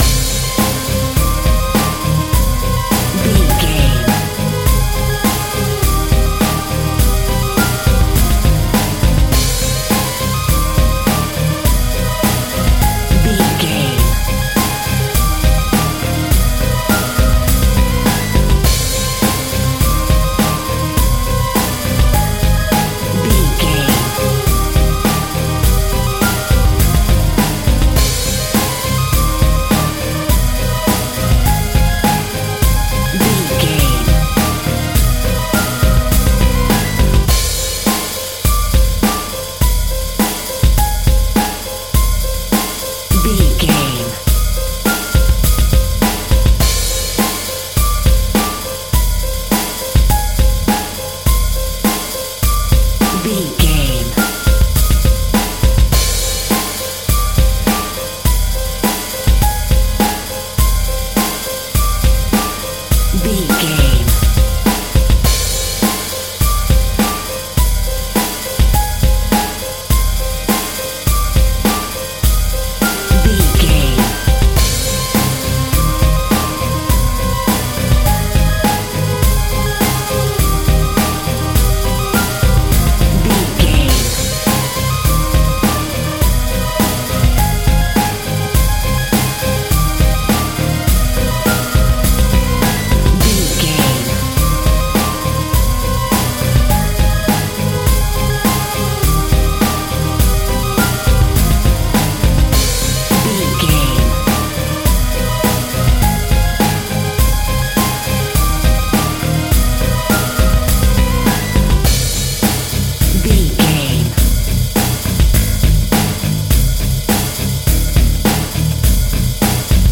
Thriller Action Music Sound.
Aeolian/Minor
Fast
tension
ominous
dark
suspense
eerie
industrial
heavy
epic
drums
electric piano
strings
electric guitar
synth
pads